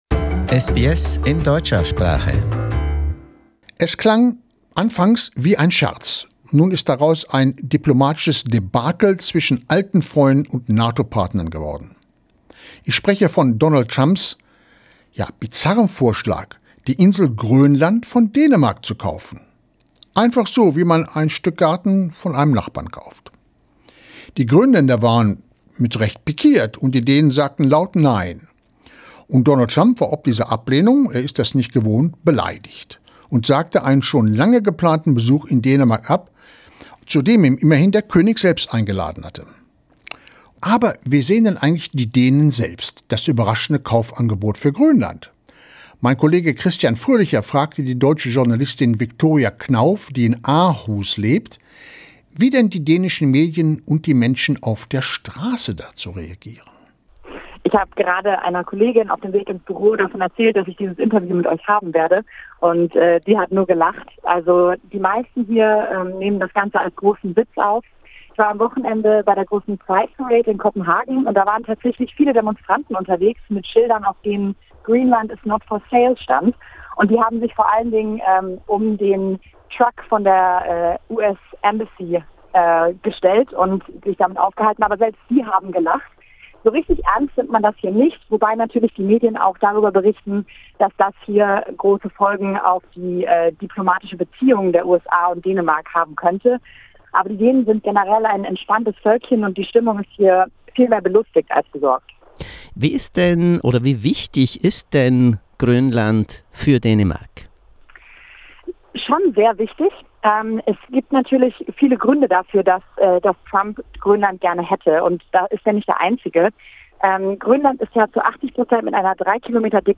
We asked her what the Danes think about Donald Trump’s attempt to purchase Greenland, the world’s biggest island. Danish Prime Minister Mette Frederiksen had described the attempt as “absurd”, prompting Trump to unilaterally cancel an official state visit to Copenhagen.